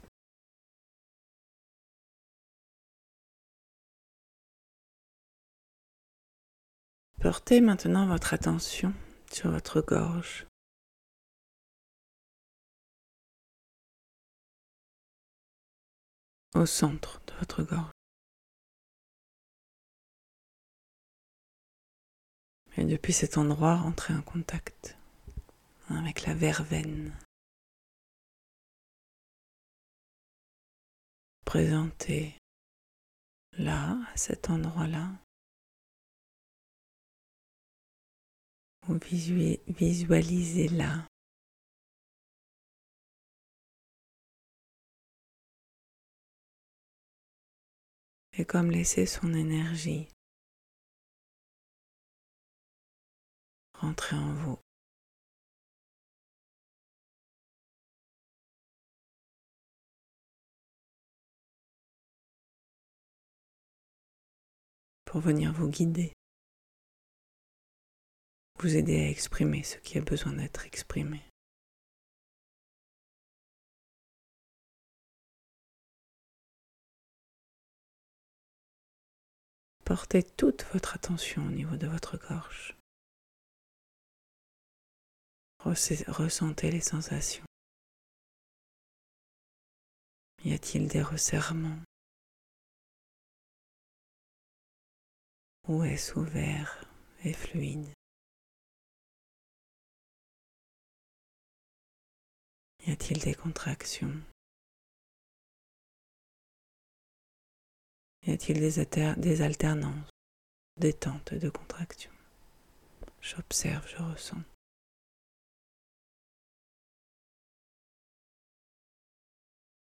Les méditations guidées sont une proposition pour entrer en lien plus intime avec la plante et ressentir l’interaction qui peut s’opérer entre elle et nous.